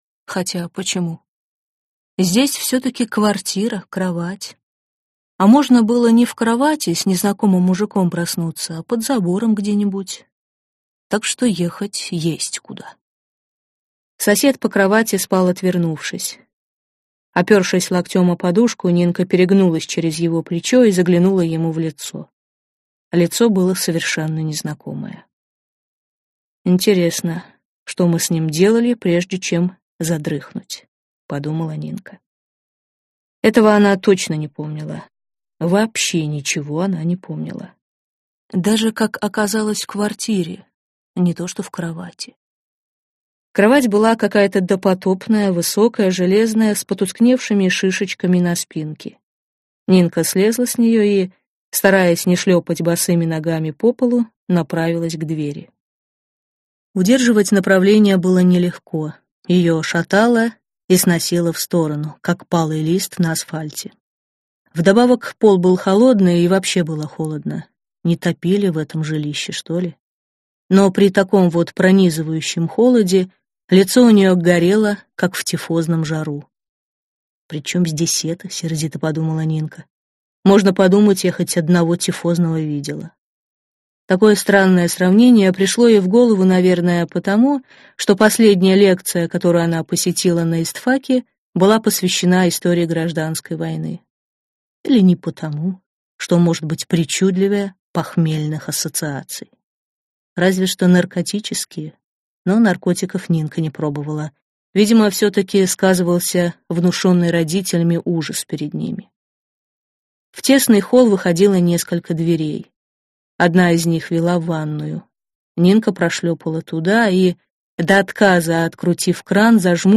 Аудиокнига Французская жена | Библиотека аудиокниг
Прослушать и бесплатно скачать фрагмент аудиокниги